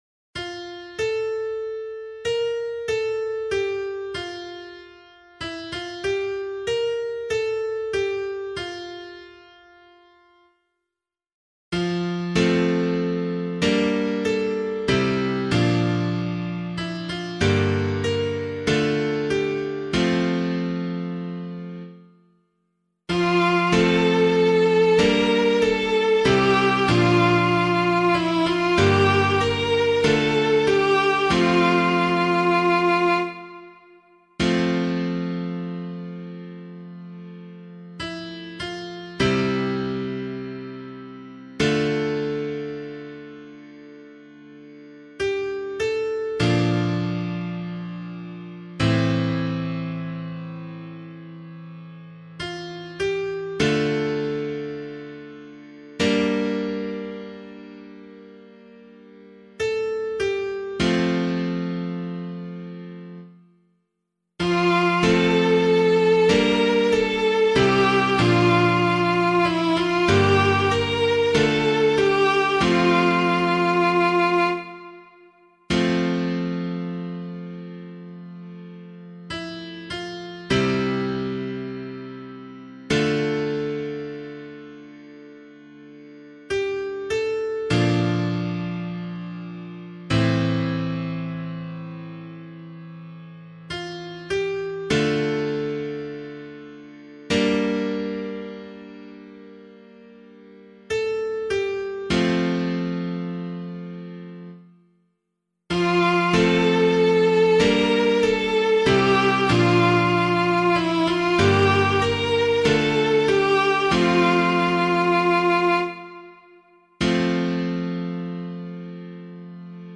pianovocal
068 Christ the King Psalm A [LiturgyShare 6 - Oz] - piano.mp3